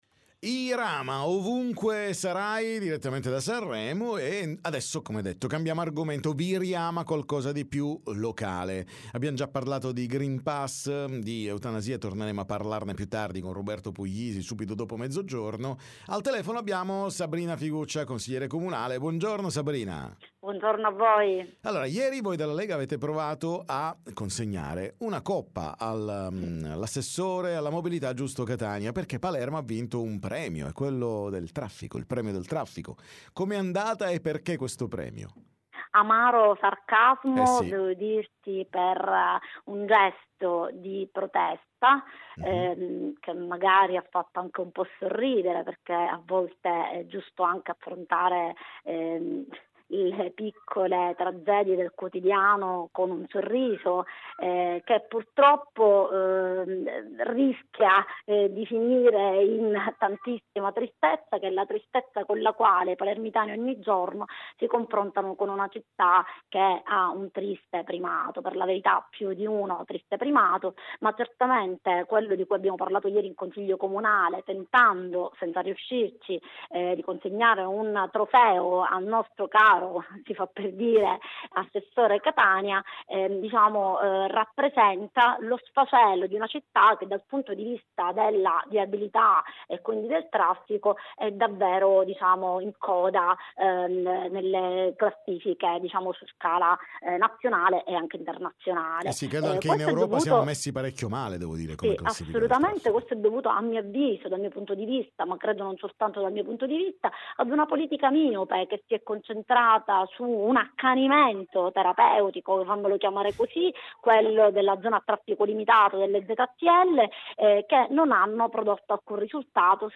TM Intervista Sabrina Figuccia